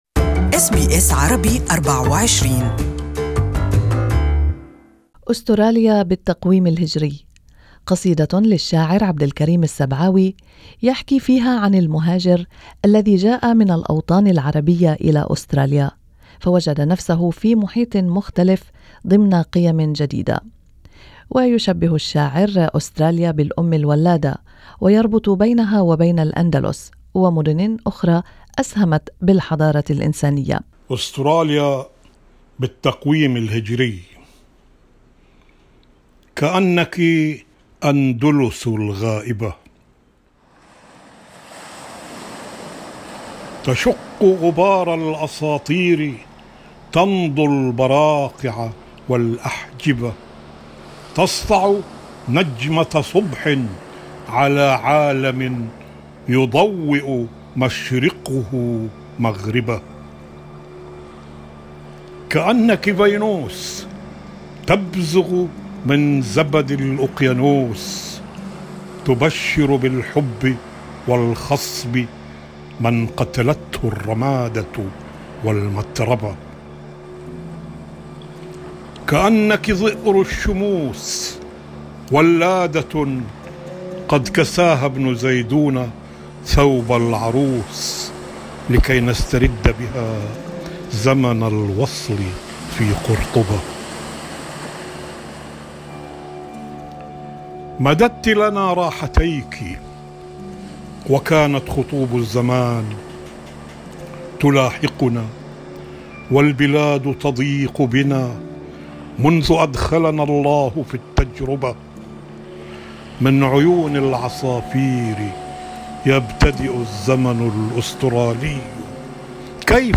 استمعوا الى القصيدة بصوت الشاعر